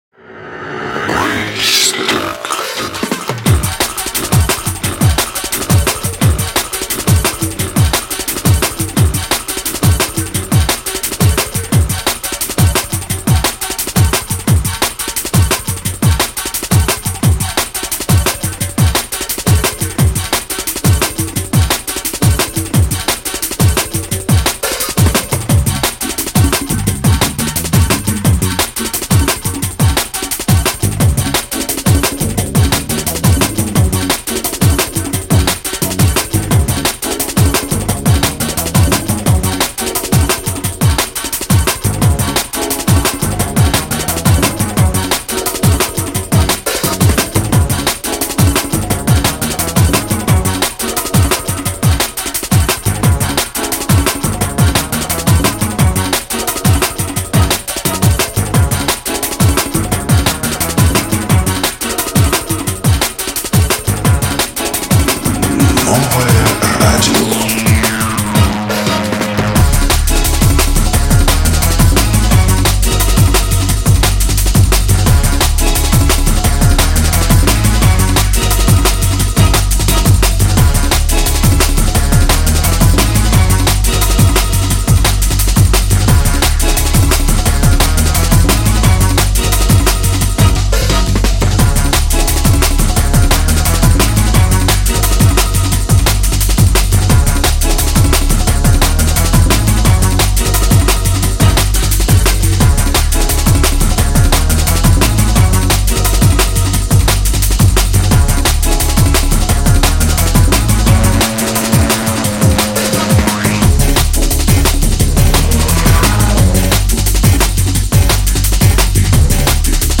breakbeat, jump up, liquid funk